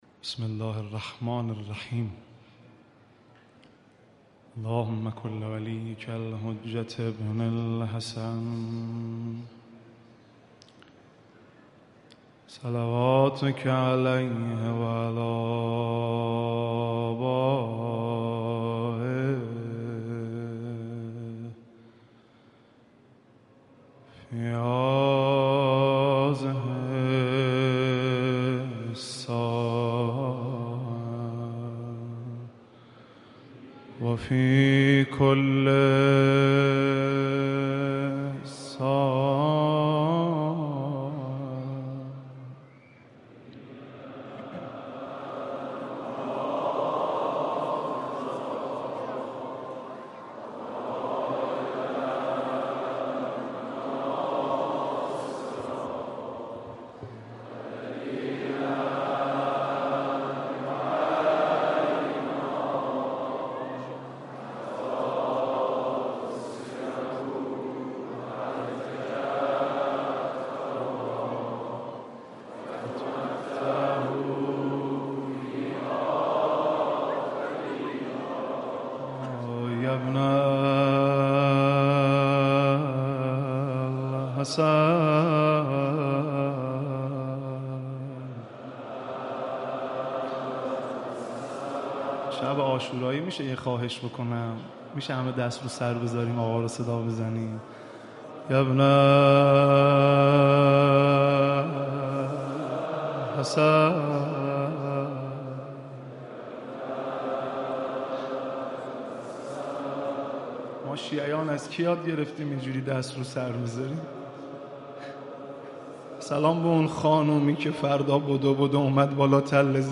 مداحی
در حسینیۀ امام خمینی (ره) در شب عاشورا
مرثیه‌سرایی و نوحه‌خوانی